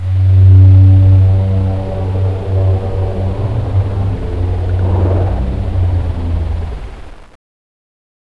RobotWhaleScream-004.wav